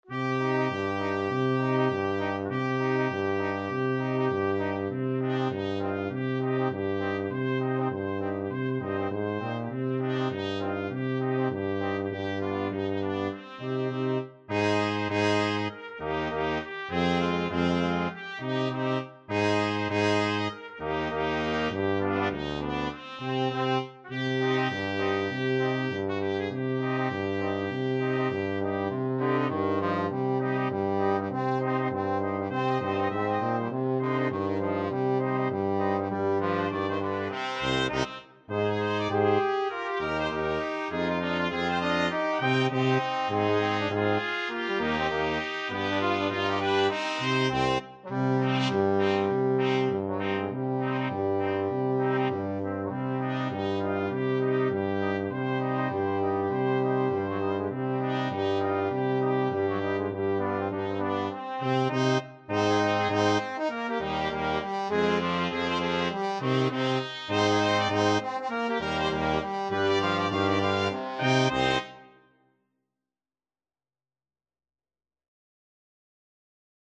(2tp, tb, tu)
Trumpet 1Trumpet 2TromboneTuba
2/2 (View more 2/2 Music)
Firmly, with a heart of oak! Swung = c.100